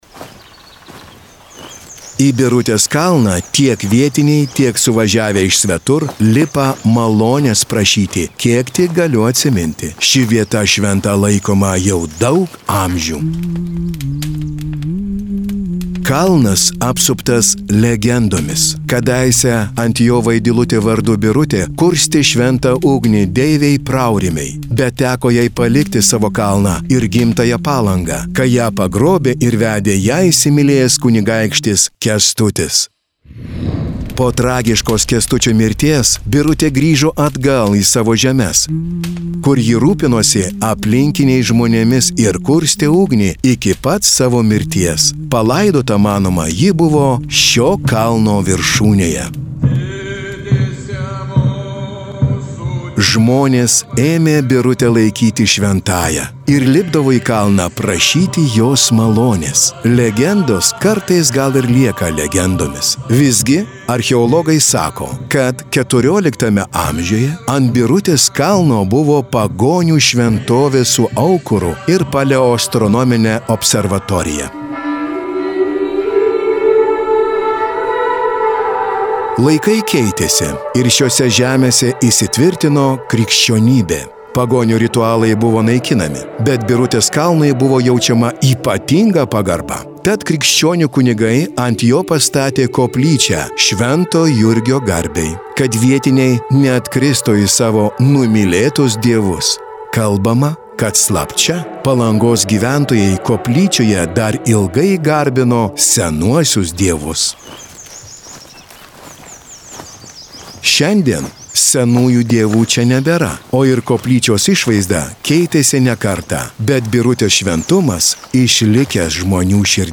Projekto „Kurortų paveldas išmaniai“ Palangos objektų audiogidai: